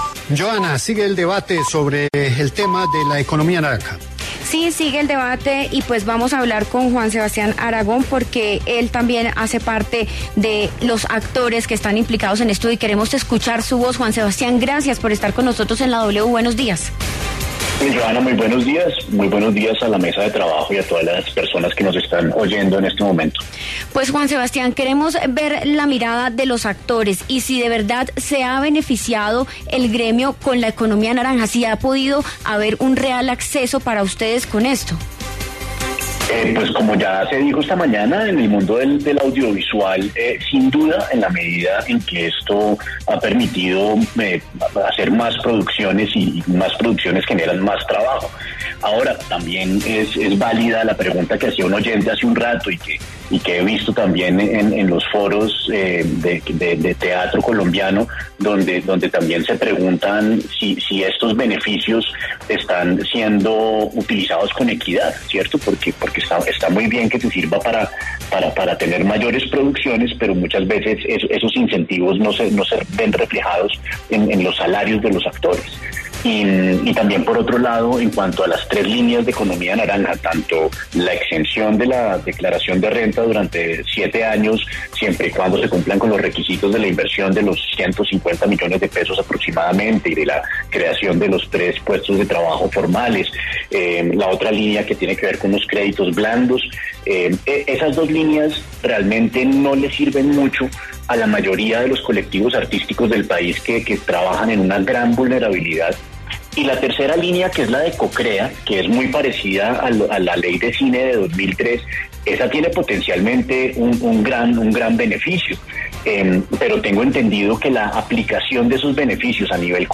En diálogo con La W, Juan Sebastián Aragón aseguró que la Economía Naranja ha permitido hacer más producciones y, por ende, ha brindado más trabajo.